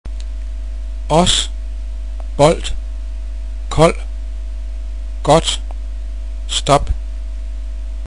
Danish Vowels
[å] hos [hos] (with/at), på [på'], (on), ost [åsd] (cheese)
åben [å:b(æ)n] (open), låne [lå:næ] (borrow), låse [lå:sæ] (lock)